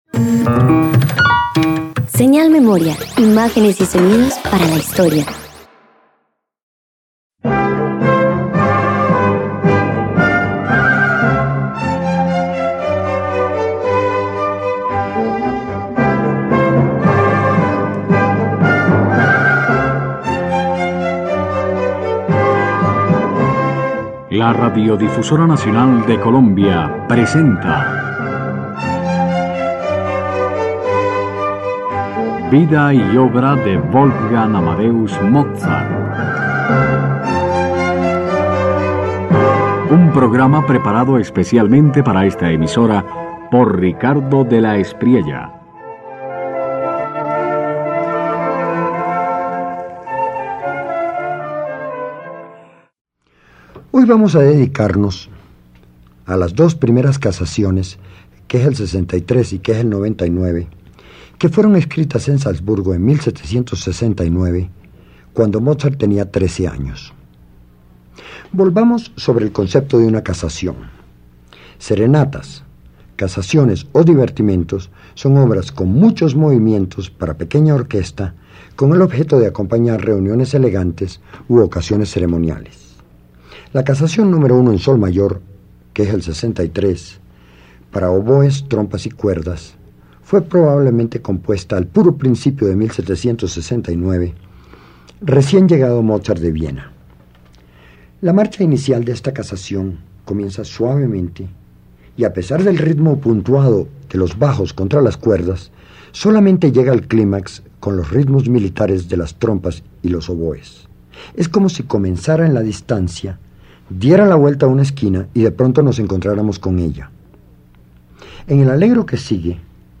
En sus casaciones, Mozart ofrece música ceremonial que combina orden y frescura, revelando su capacidad para ennoblecer incluso lo circunstancial. Así, lo que parecía ser solo protocolario se transforma en invención sonora con vida propia.